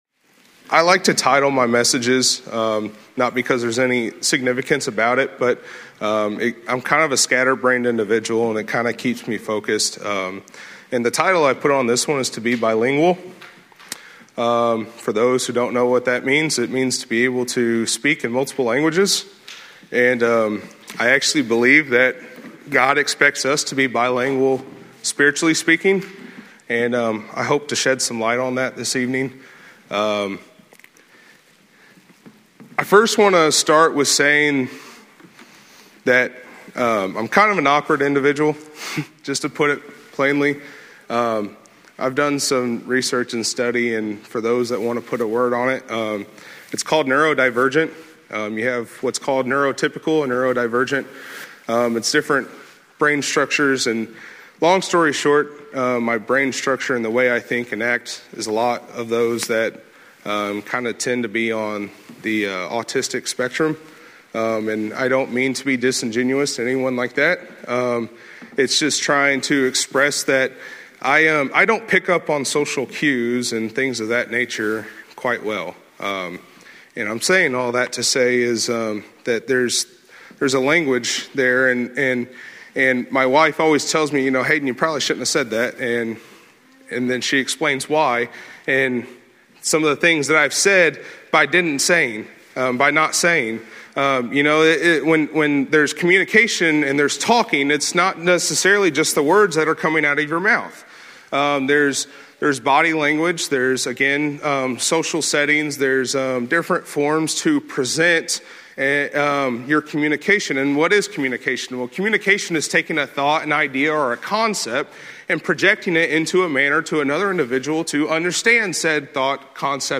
From Series: "Sunday Evening Sermons"